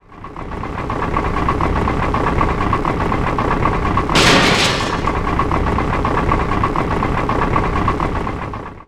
dronein.wav